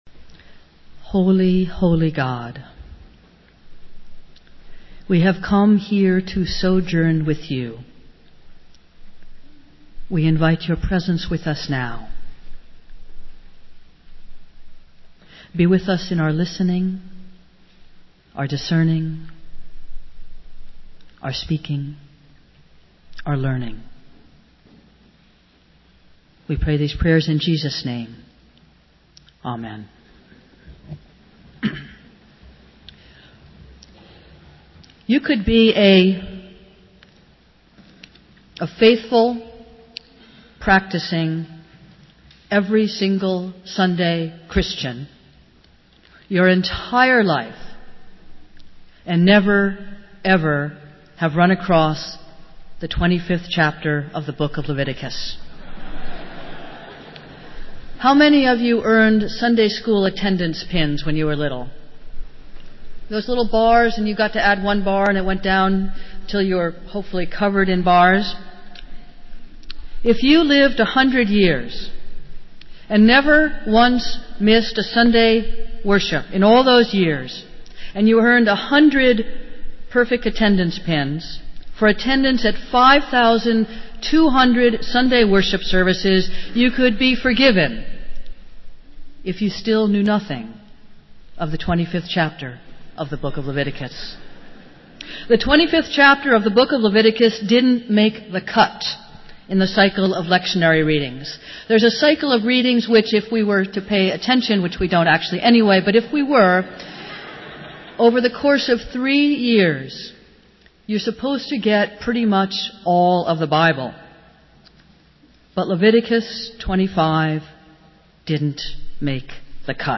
Festival Worship - Fifth Sunday of Lent